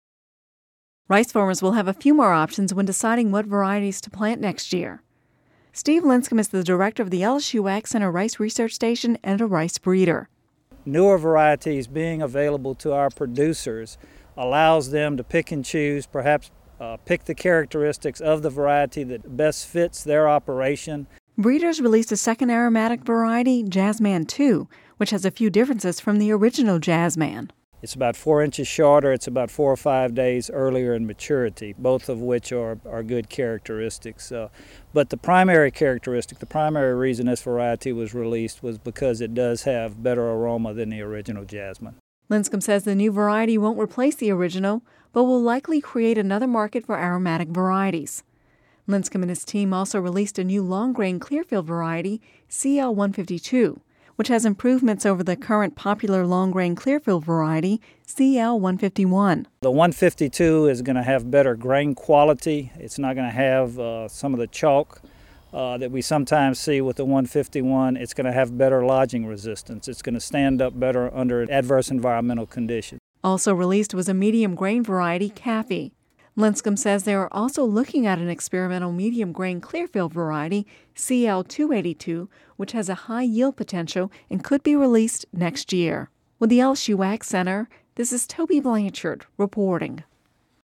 (Radio News 07/12/11) Rice farmers will have a few more options when deciding what varieties to plant next year. Breeders released a second aromatic variety, Jazzman-2, which has a few differences from the original Jazzman.